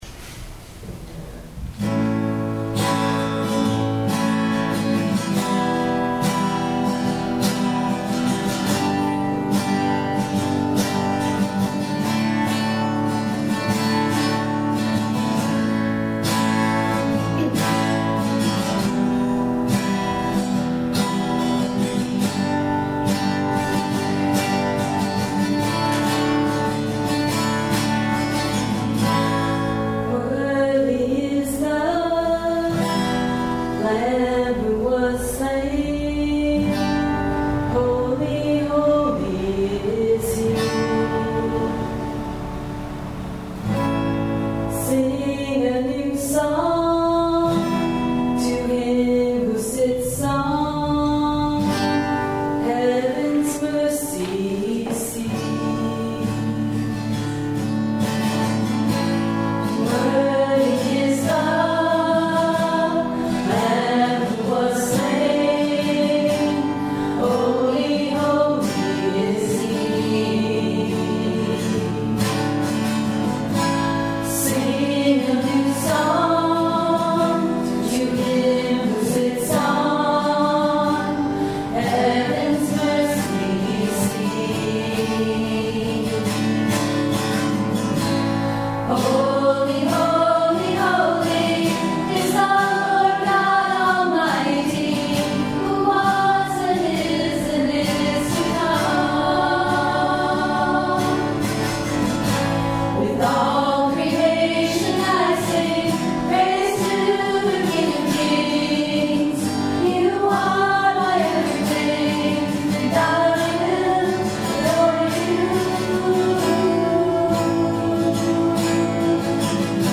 Pentecost, Christ Lutheran Church, Troy, NH (audio only)